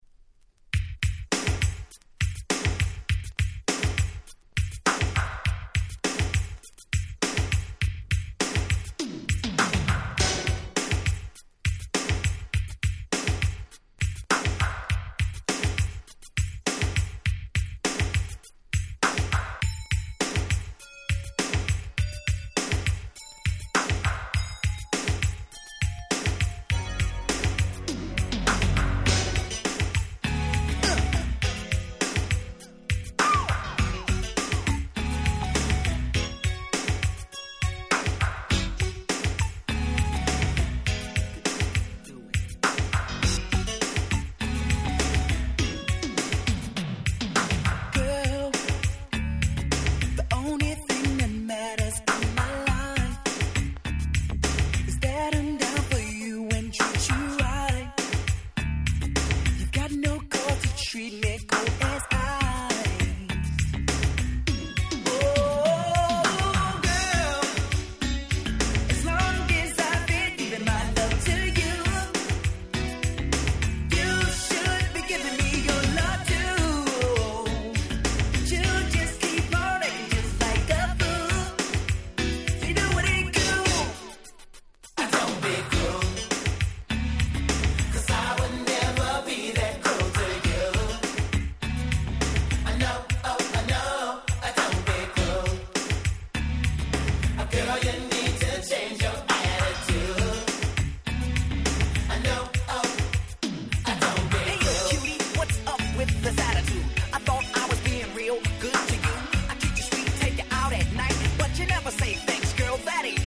88' Super Hit New Jack Swing !!